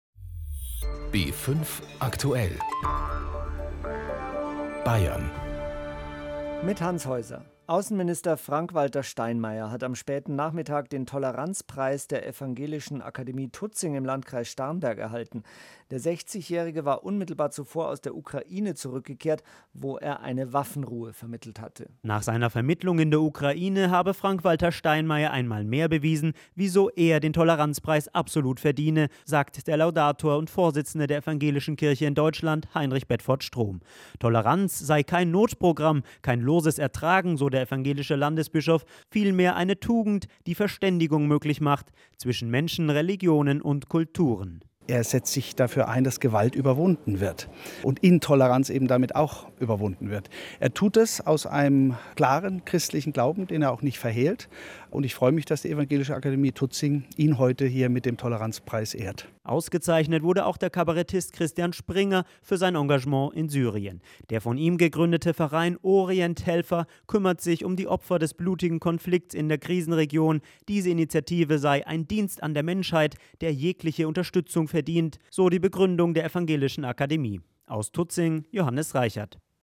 Einen Hörfunkbeitrag